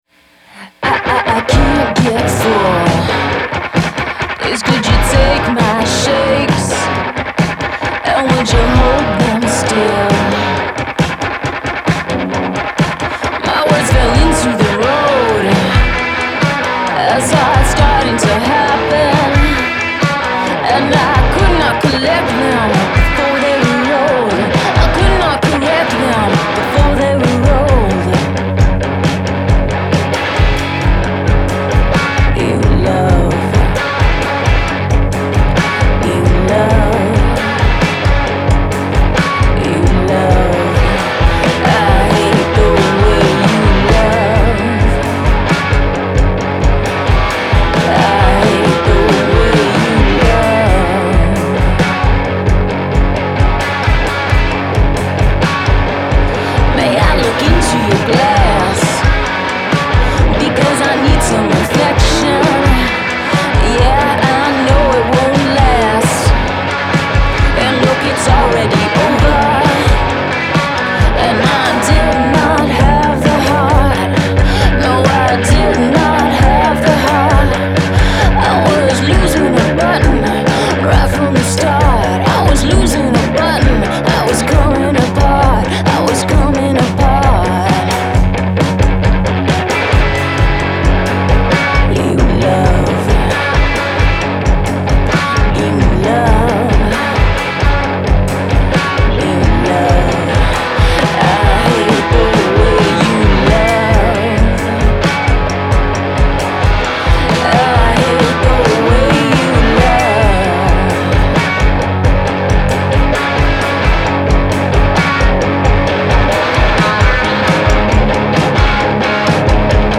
Genre : Pop, Rock, Alternatif et Indé